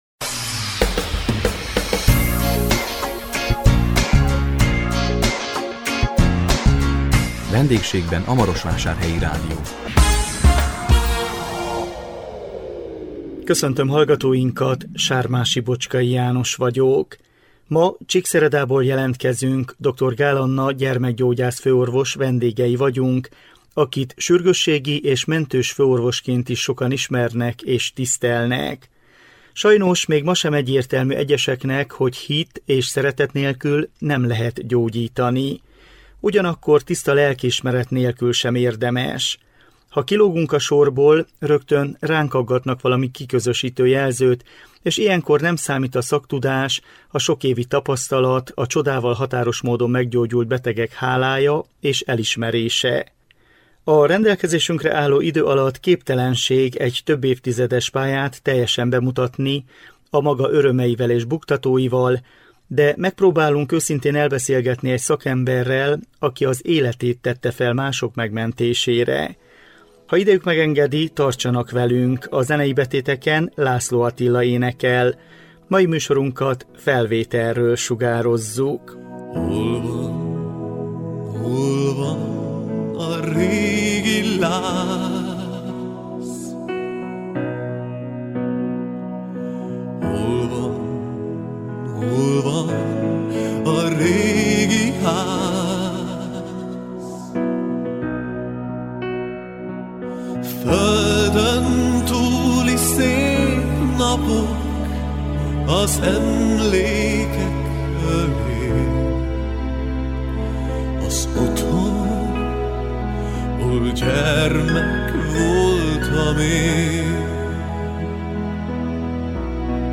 A 2022 október 27-én jelentkező VENDÉGSÉGBEN A MAROSVÁSÁRHELYI RÁDIÓ című műsorunkkal Csíkszeredából jelentkeztünk